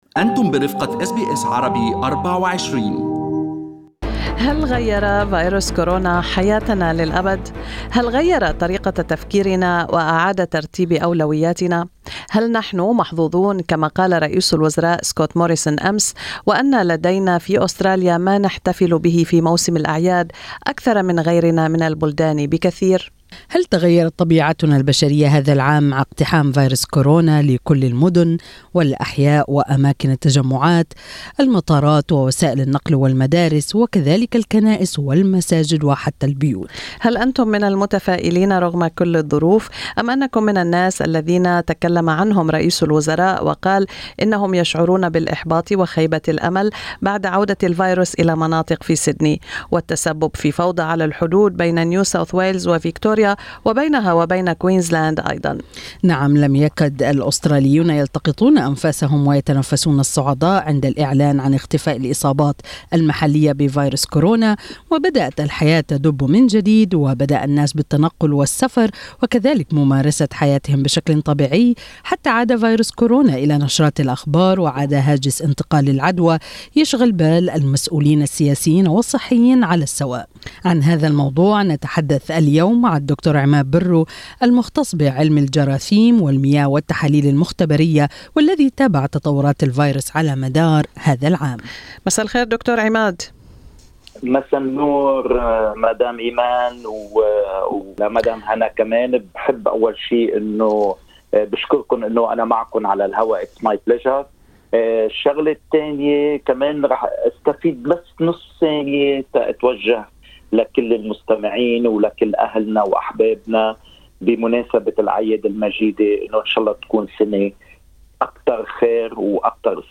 استمعوا إلى اللقاء كاملا تحت المدونة الصوتية في أعلى الصفحة.